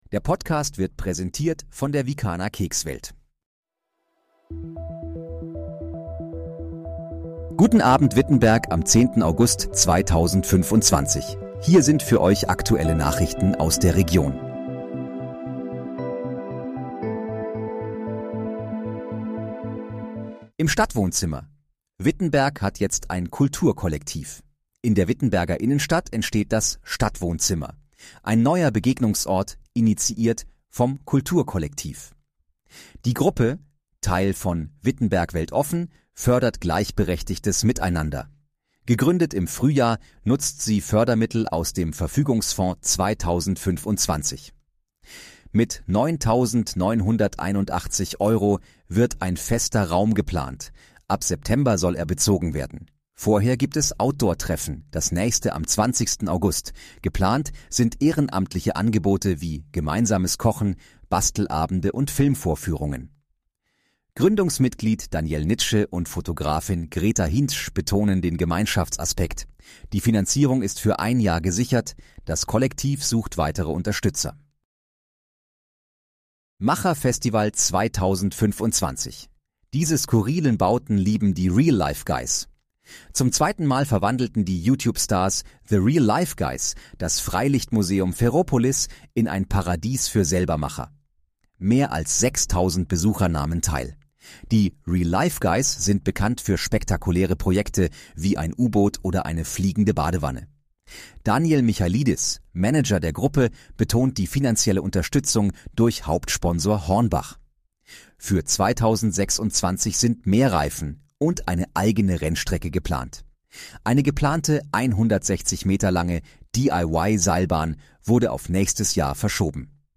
Guten Abend, Wittenberg: Aktuelle Nachrichten vom 10.08.2025, erstellt mit KI-Unterstützung
Nachrichten